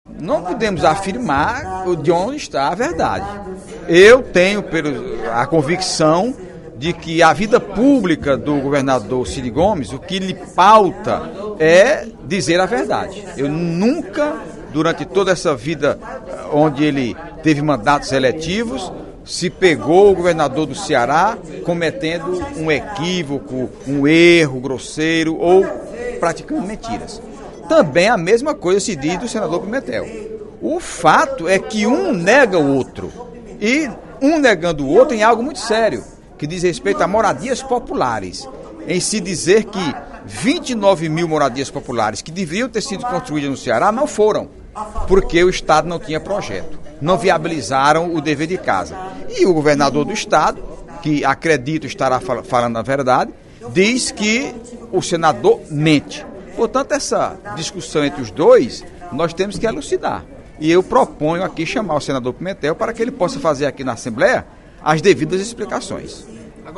O deputado Heitor Férrer (PDT) fez pronunciamento nesta quinta-feira (16/02) na Assembleia Legislativa para comentar as declarações do governador Cid Gomes contra o senador José Pimentel (PT-CE).